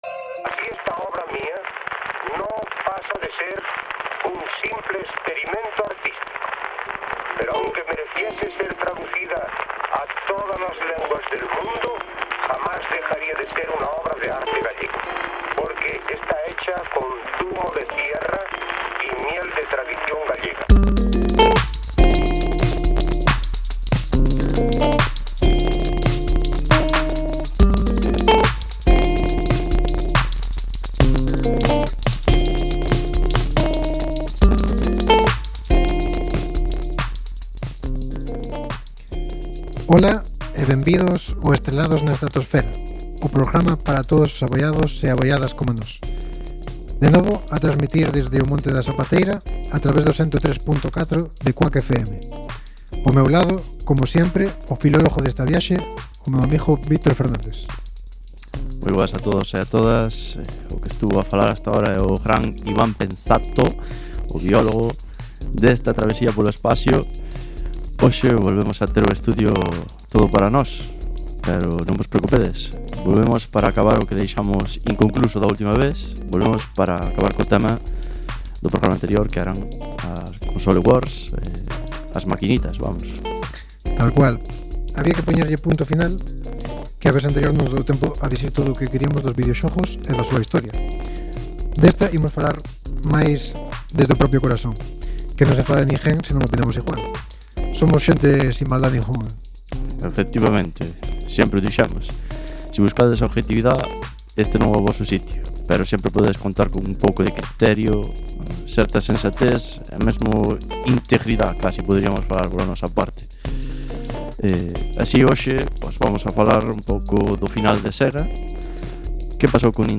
PD: a gravación no estudio de CUAC, provocou un "crepitar" no audio do programa nos 2 primeiros minutos. A partir de ese momento, o audio escóitase perfectamente.